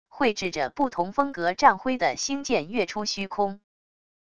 绘制着不同风格战徽的星舰跃出虚空wav音频